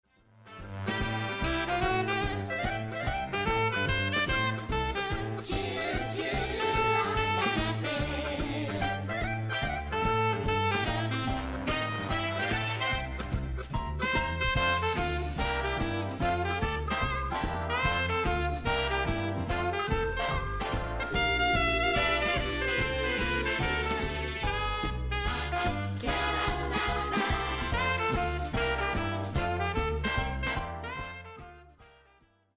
Genre: Blues.
SZ-Jazz-Sax1.mp3